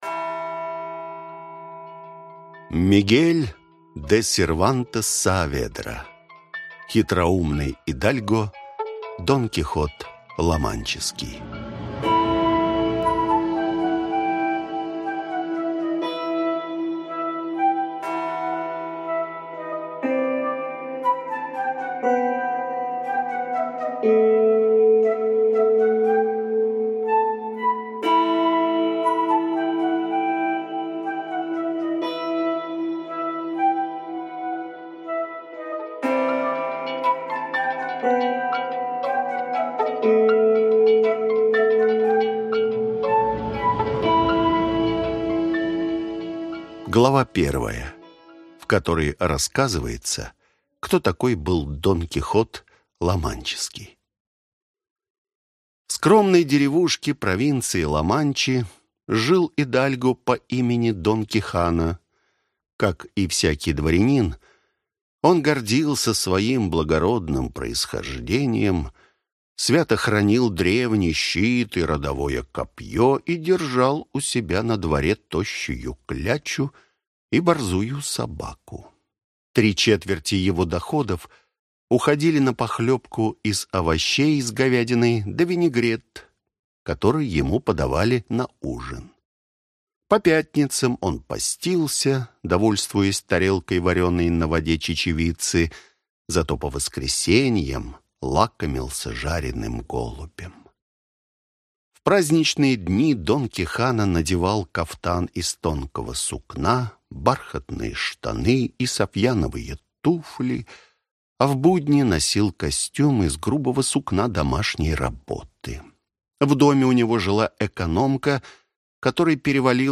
Аудиокнига Хитроумный идальго Дон Кихот Ламанчский | Библиотека аудиокниг